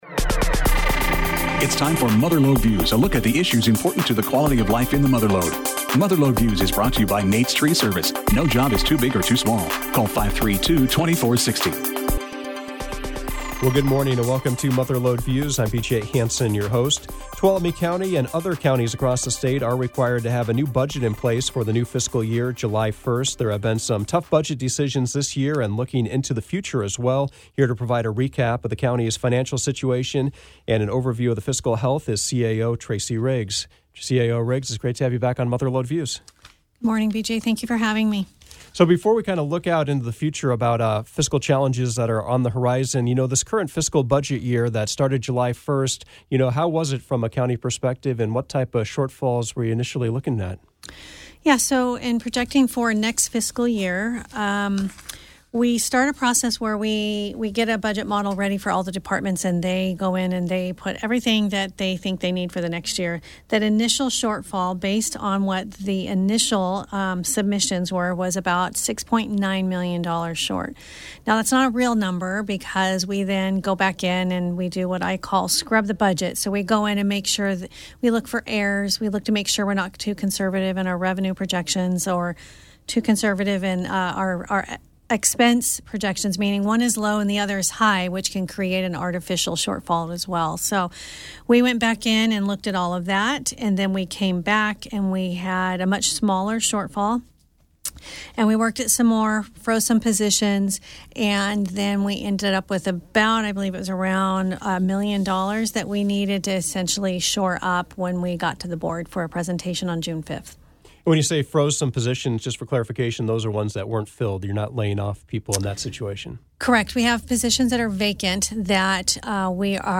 Mother Lode Views featured Tuolumne County Administrator Tracie Riggs recapping the new county budget for the 2024-25 fiscal year that started on July 1st.